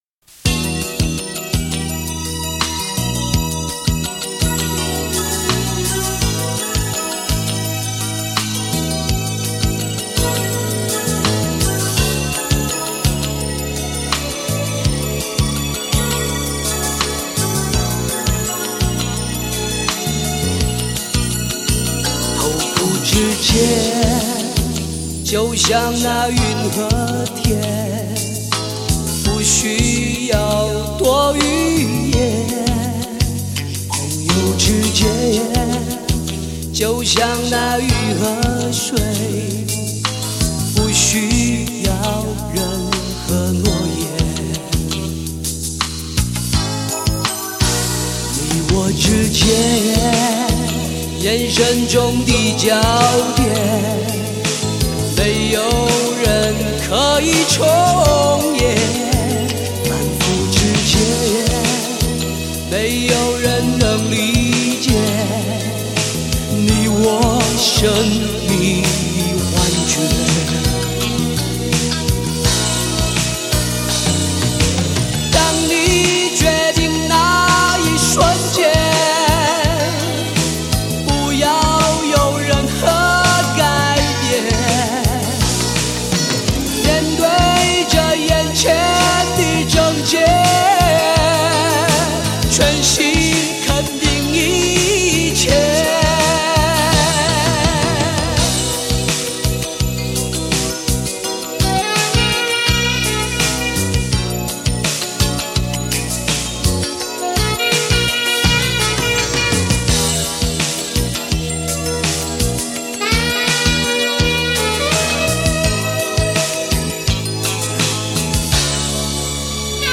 整个专辑气氛活跃谐趣，是一张快乐的专辑。
本资源是由磁带制作的APE无损格式，来历貌似异常坎坷，但音质还是弄得很不错。